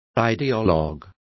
Complete with pronunciation of the translation of ideologues.